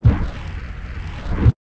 1 channel
WORMHOLE.mp3